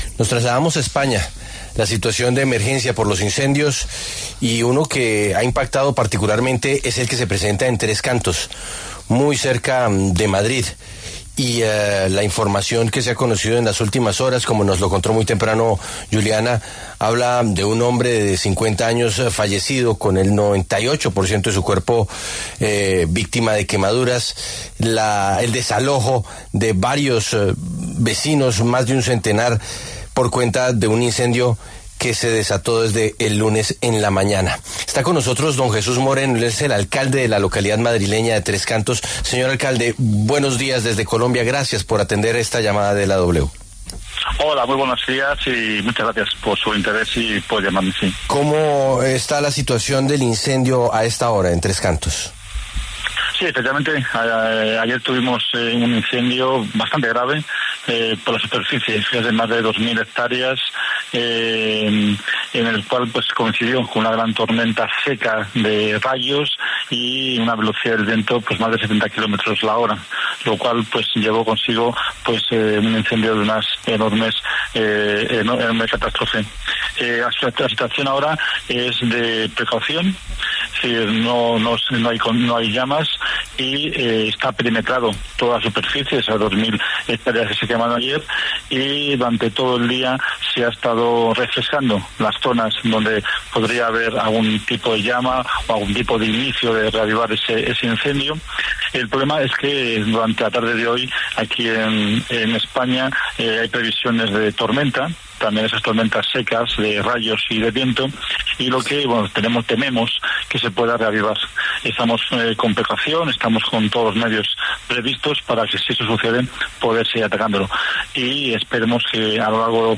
Jesús Moreno, alcalde de la localidad madrileña Tres Cantos en España, brindó detalles en La W sobre el incendio en esta zona.